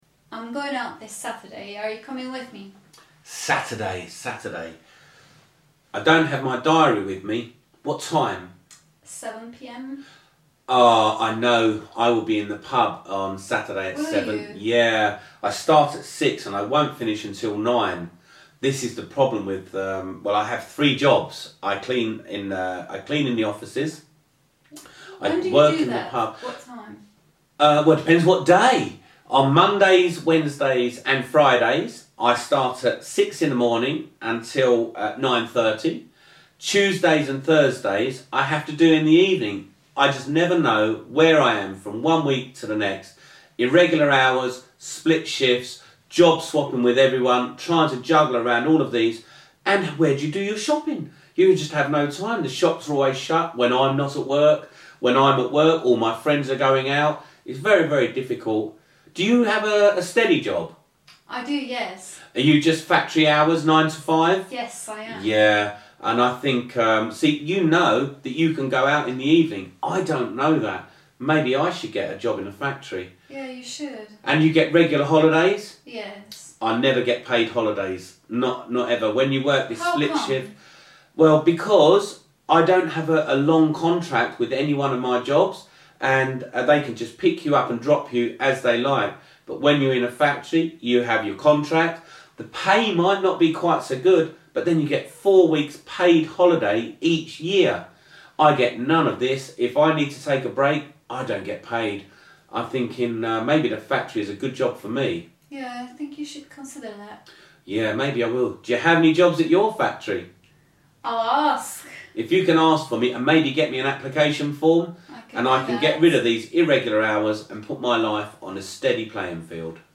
Ukázka z knihy
Polish Your Rusty English - Listening Practice 5 - unikátní a ojedinělá sbírka autentických rozhovorů anglických rodilých mluvčích.